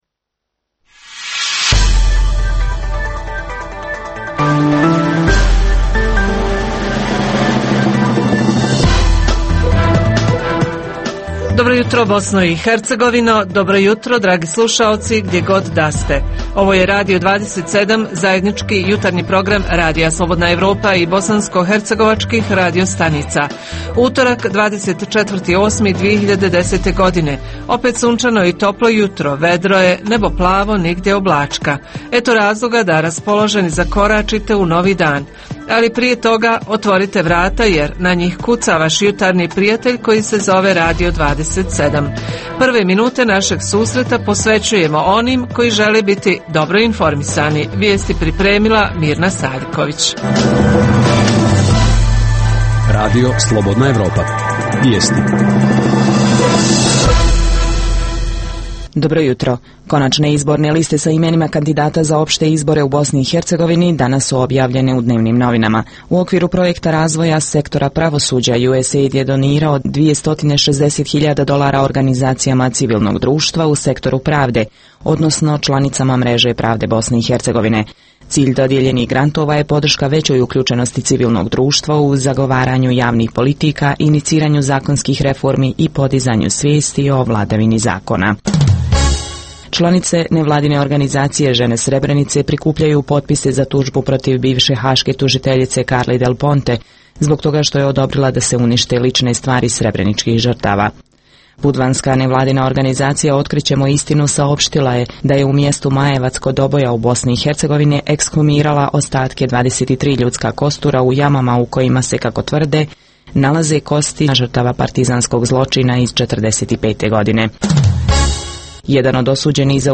Jutarnji program za BiH donosi: Reporteri Radija 27 iz Prijedora, Mostara Doboja, Višegrada javljaju o tome kako protiče ljeto u njihovom gradu, ima li zanimljivih kulturnih sadržaja, gdje građani pronalaze rashlađenje i osvježenje? U Mostaru večeras premijera pozorišne predstave „Murlin Murlo“, u produkciji 4 mostarska glumca. Redovna rubrika „Svijet interneta“, između ostalog je posvećena fotografiji na web-u.Redovni sadržaji jutarnjeg programa za BiH su i vijesti i muzika.